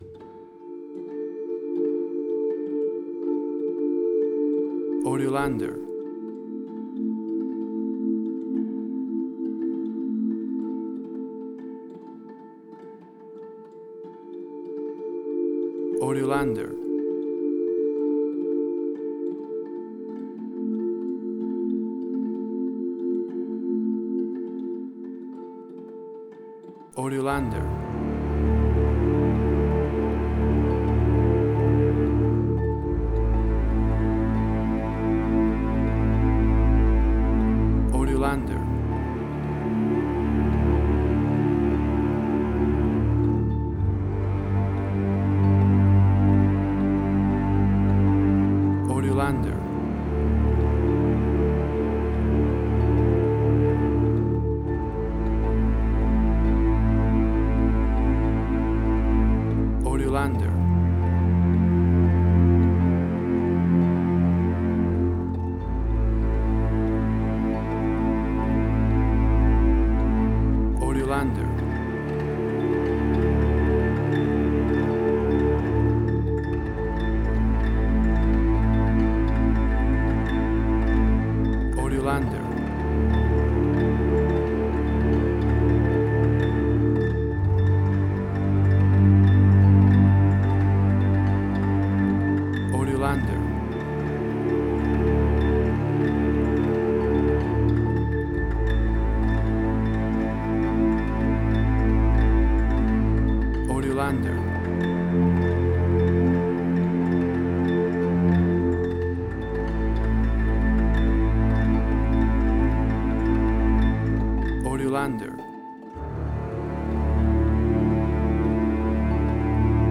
Suspense, Drama, Quirky, Emotional.